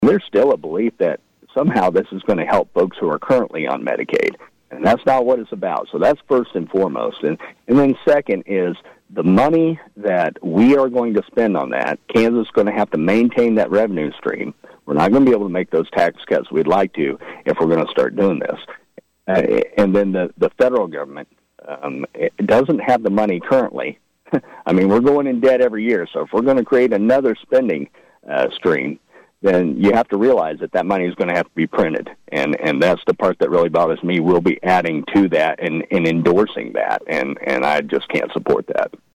Smith joined KVOE’s Morning Show Thursday to conclude KVOE’s annual legislative previews and says when it comes to the budget, he is pleased with where the state stands, especially after December tax collections topped estimates by almost 15 percent.